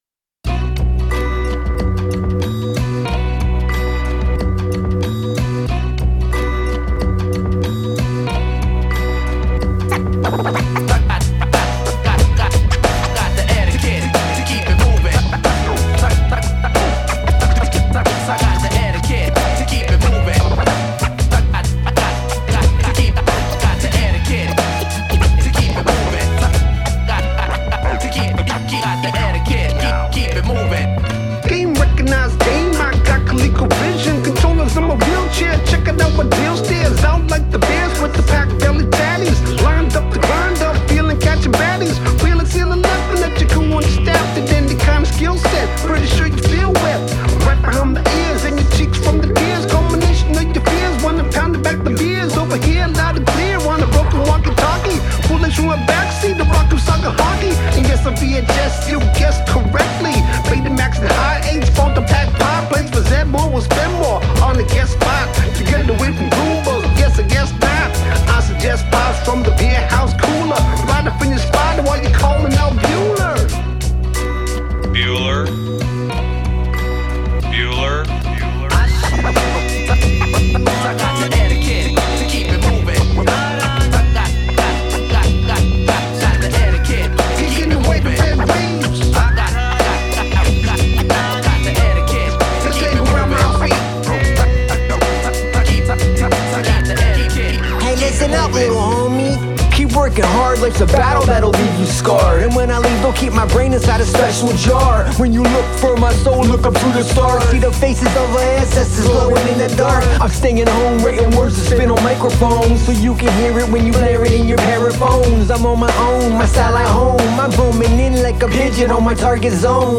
boom-bap goodness
Three tracks, zero filler, maximum head-nod.